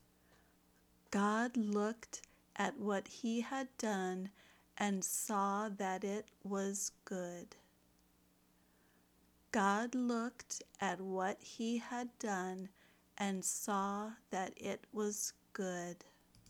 If you are learning American English, imitate her pronunciation the best you can.